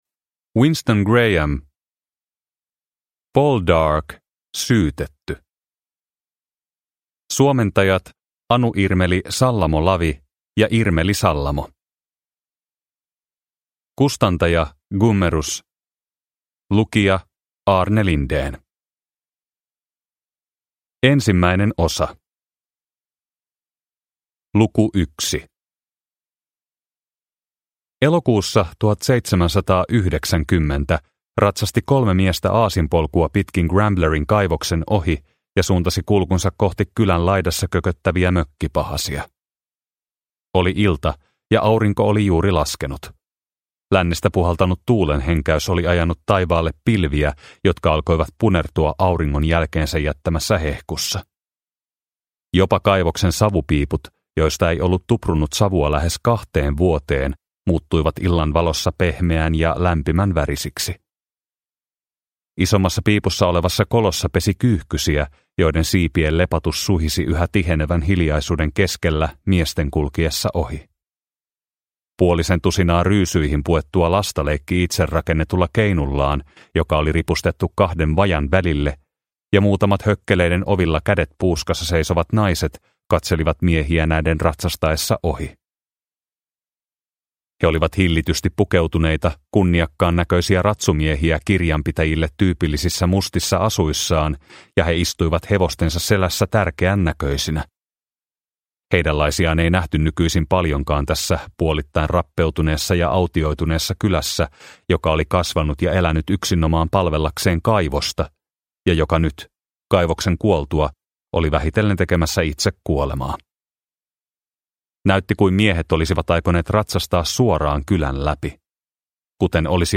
Poldark - Syytetty – Ljudbok – Laddas ner